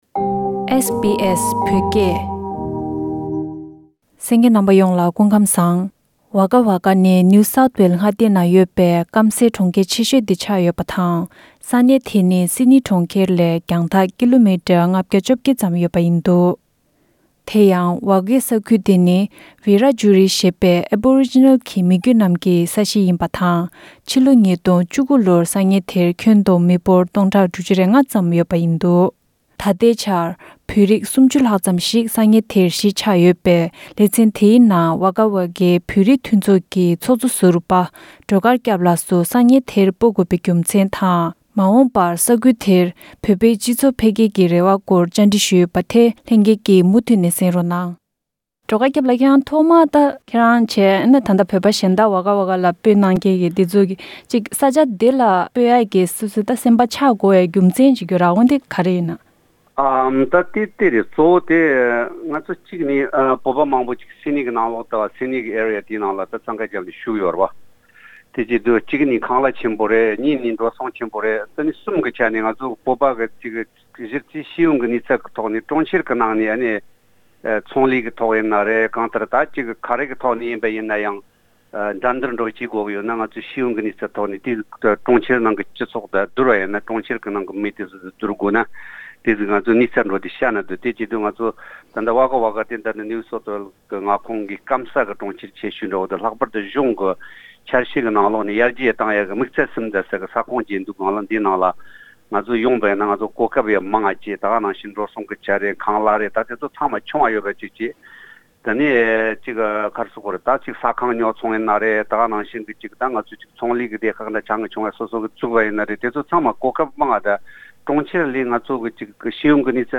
ཝ་དགའི་བོད་མིའི་གཞིས་ཆགས་ཀྱི་སྐོར་གླེང་མོལ།